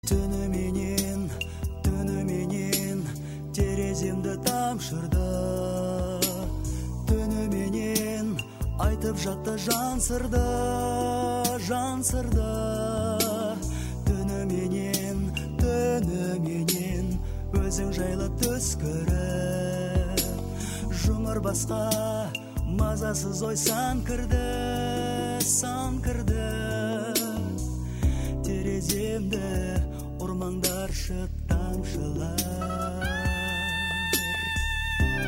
• Качество: 160, Stereo
поп
восточные мотивы
мелодичные
лиричные